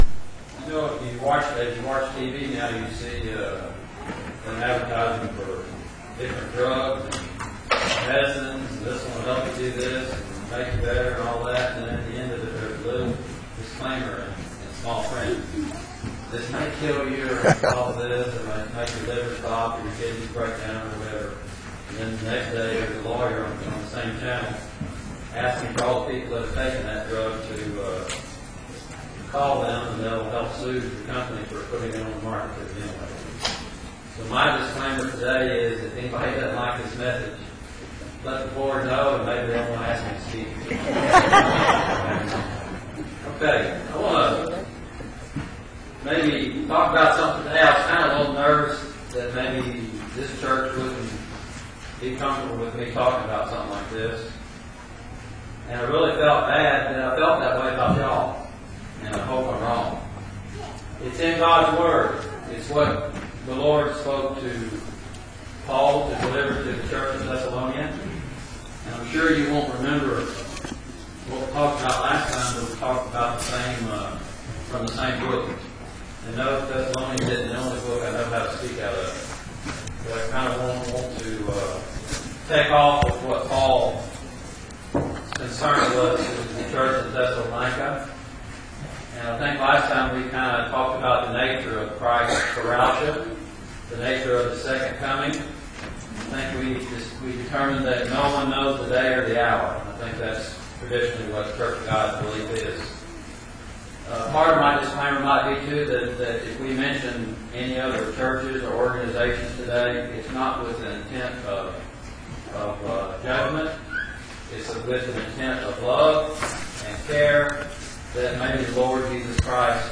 2-18-12 sermon